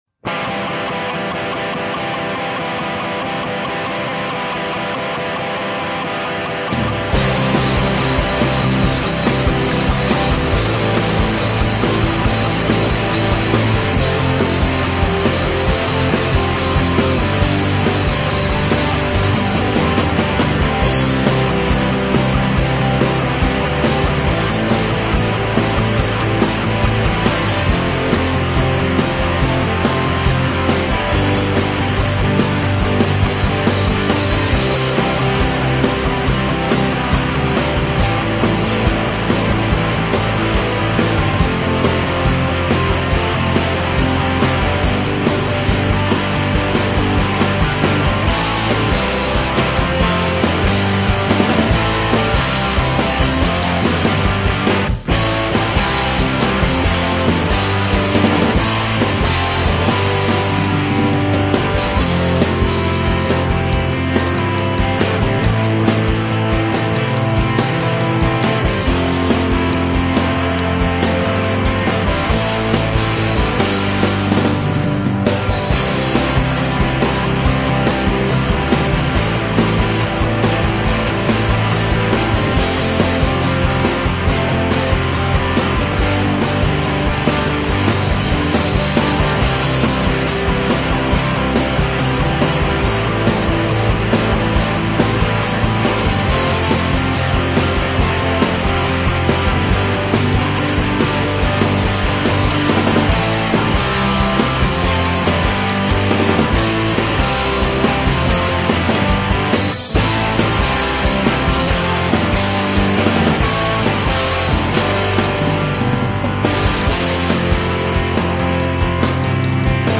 punk
metal
rock
hard rock
high energy rock and roll